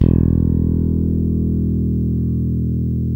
Index of /90_sSampleCDs/Roland L-CDX-01/BS _Jazz Bass/BS _Jazz Basses